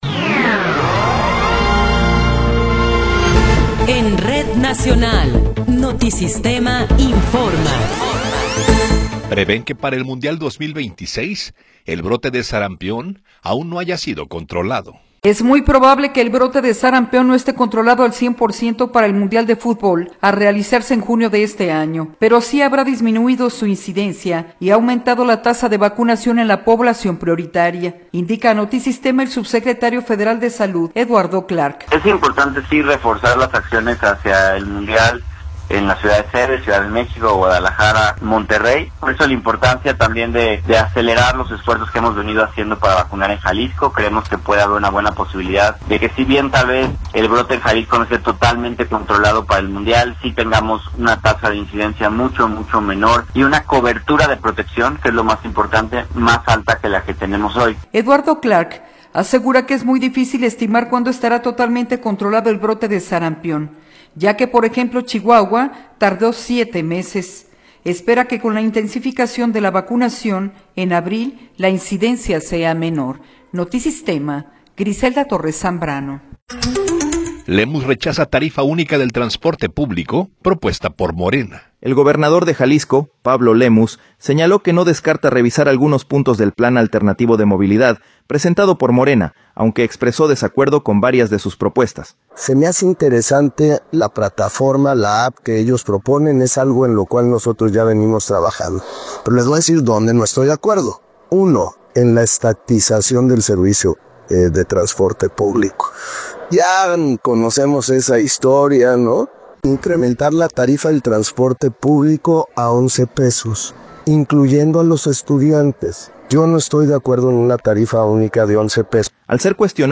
Noticiero 13 hrs. – 16 de Febrero de 2026
Resumen informativo Notisistema, la mejor y más completa información cada hora en la hora.